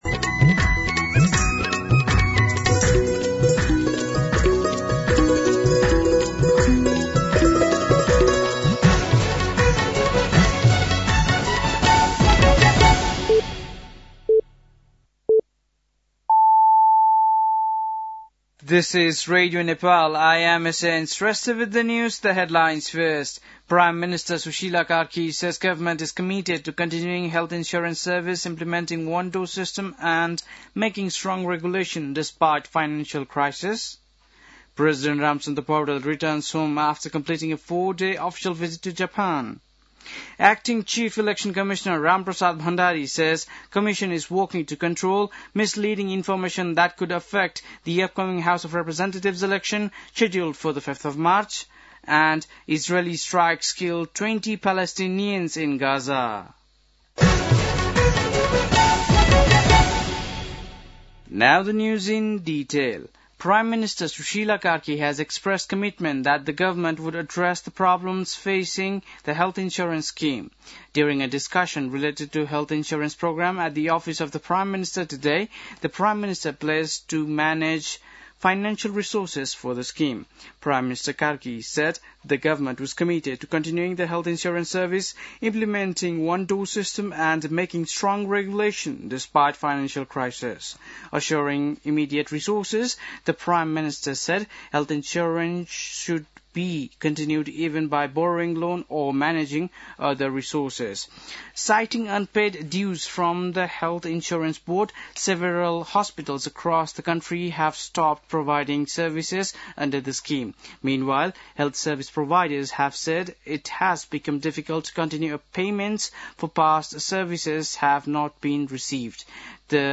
बेलुकी ८ बजेको अङ्ग्रेजी समाचार : २१ माघ , २०८२
8-pm-news-10-21.mp3